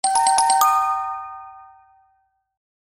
モダンで魅力的なスマートフォン通知音です。